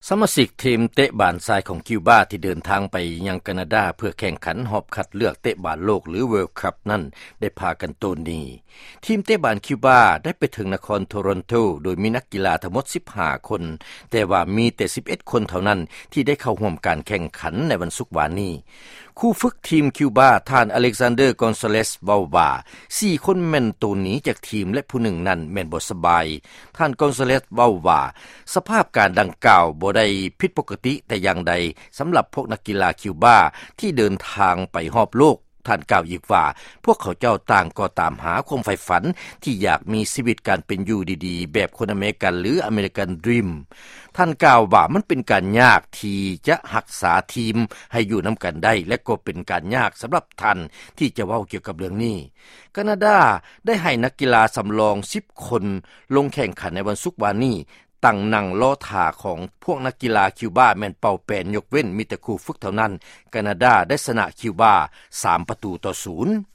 ຟັງຂ່າວ ນັກກິລາ ເຕະບານຄິວບາ